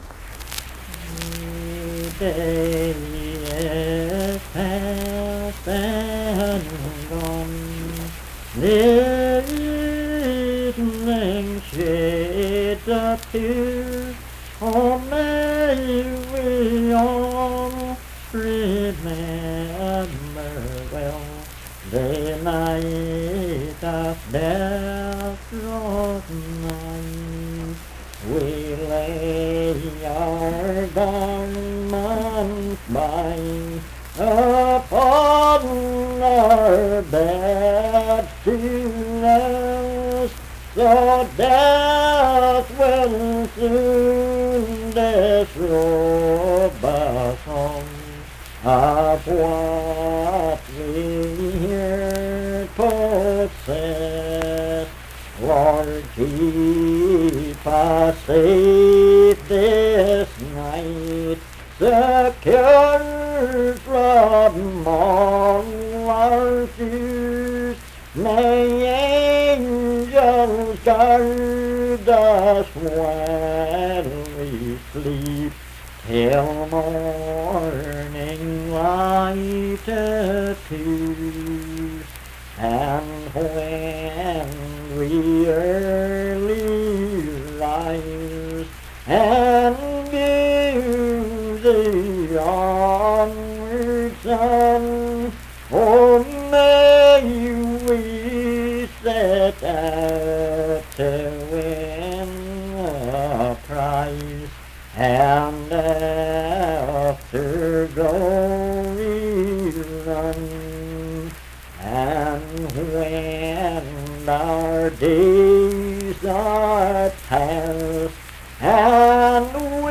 Unaccompanied vocal music
Performed in Dundon, Clay County, WV.
Death--Tragedy and Suicide, Hymns and Spiritual Music
Voice (sung)